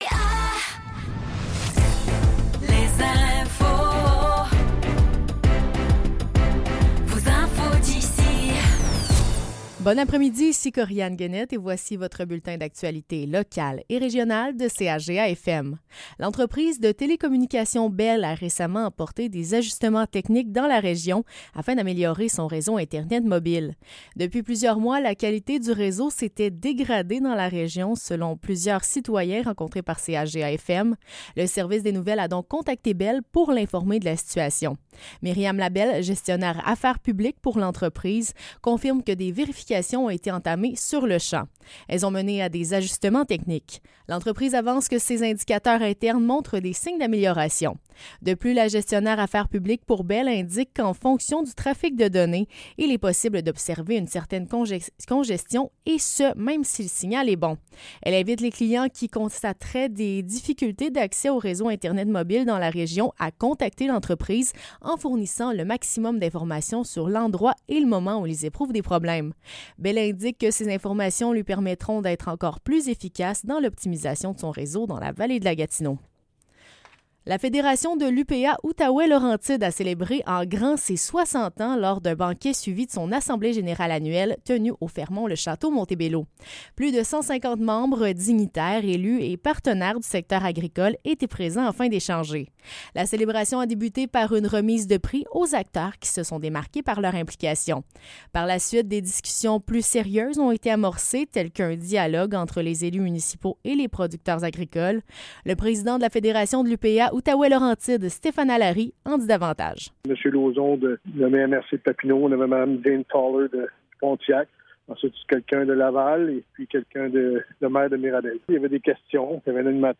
Nouvelles locales - 7 novembre 2024 - 15 h